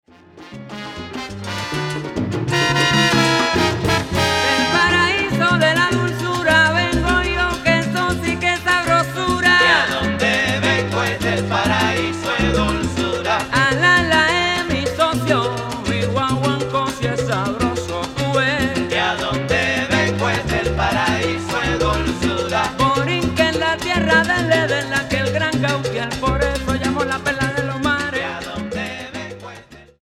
Salsa Charts - Februar 2011